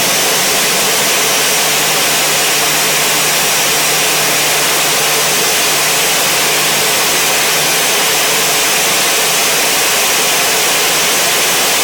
cfm-idleClose.wav